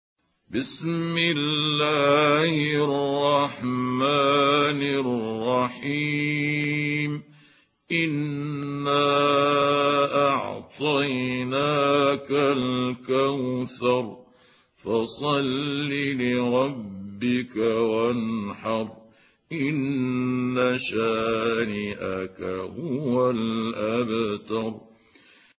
سورة الكوثر | القارئ محمود عبد الحكم
سورة الكوثر مكية عدد الآيات:3 مكتوبة بخط عثماني كبير واضح من المصحف الشريف مع التفسير والتلاوة بصوت مشاهير القراء من موقع القرآن الكريم إسلام أون لاين